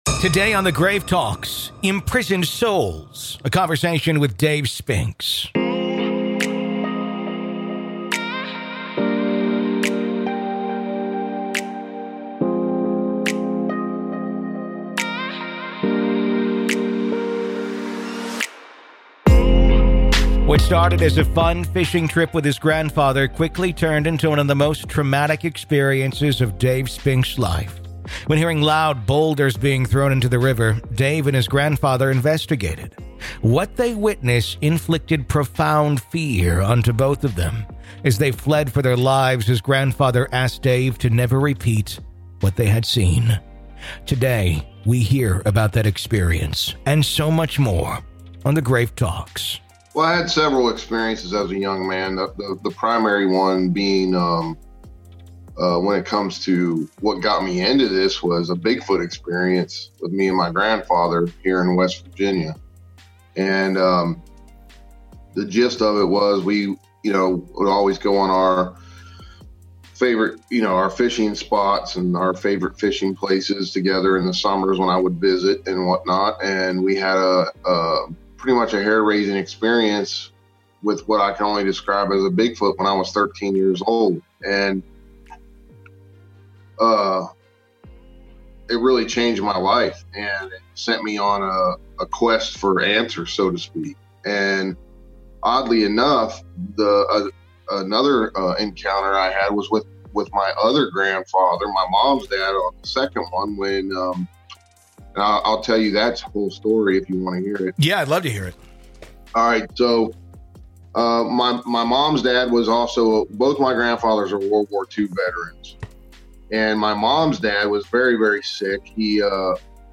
It’s a conversation about fear, secrecy, generational warnings, and what happens when experiences defy easy explanation.